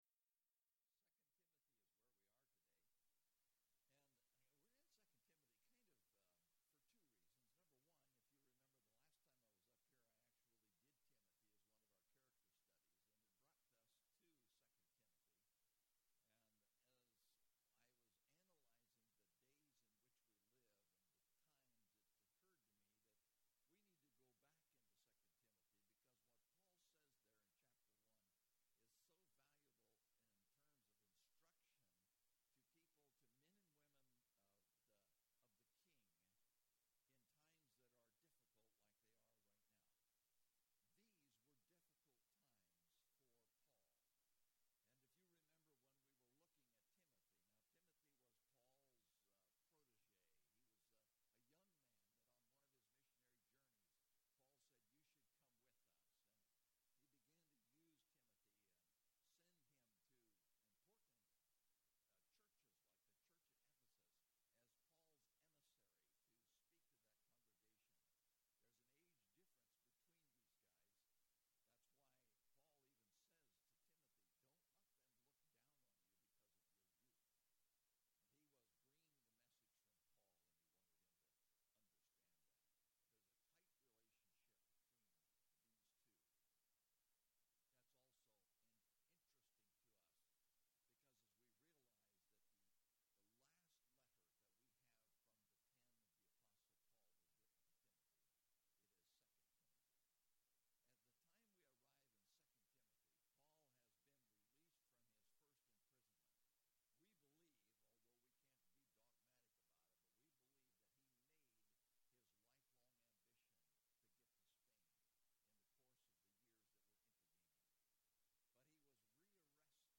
Topical Message